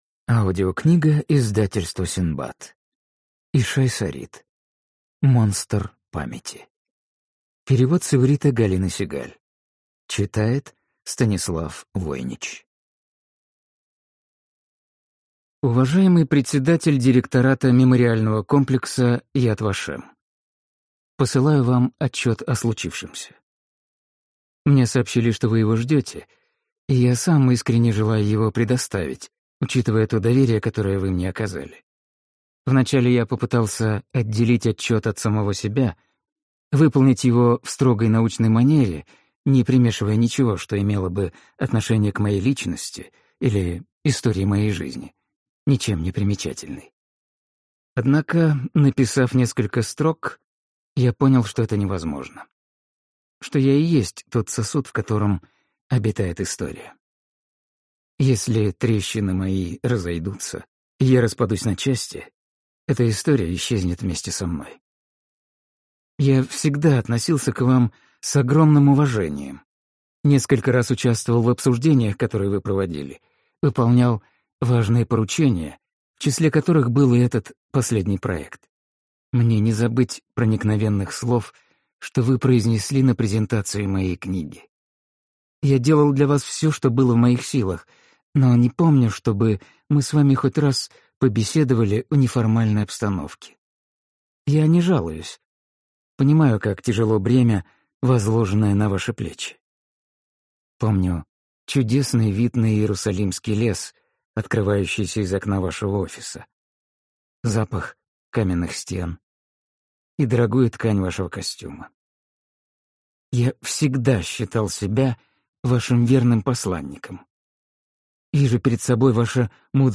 Аудиокнига Монстр памяти | Библиотека аудиокниг